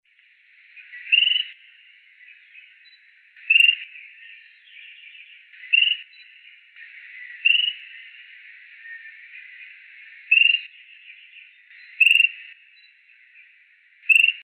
Eared Pygmy Tyrant (Myiornis auricularis)
Life Stage: Adult
Location or protected area: Reserva Privada y Ecolodge Surucuá
Condition: Wild
Certainty: Photographed, Recorded vocal